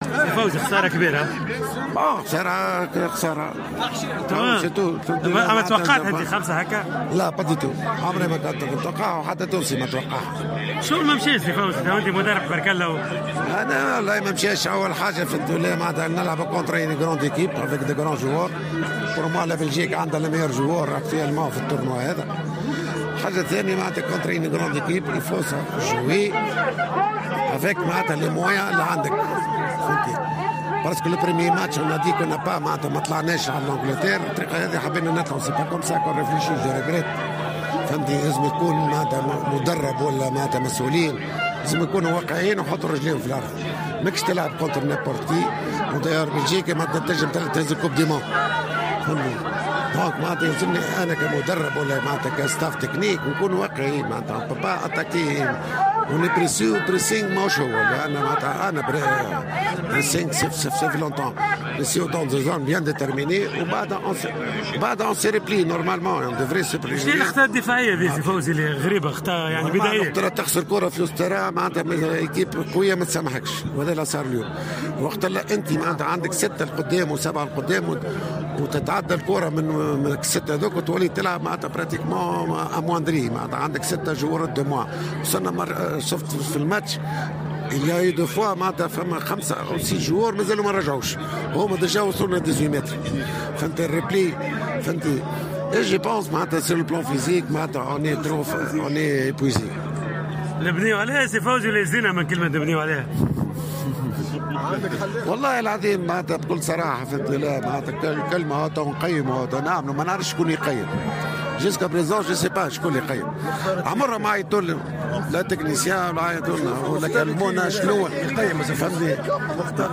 أكد المدرب فوزي البنزرتي ، الذي كان متواجدا في موسكو لمتابعة مقابلة المنتخب الوطني أمام نظيره البلجيكي ، في تصريح لمبعوث جوهرة أف أم أنه لم يتوقع هذه الهزيمة بنتيجة ثقيلة مثل التي حدثت اليوم.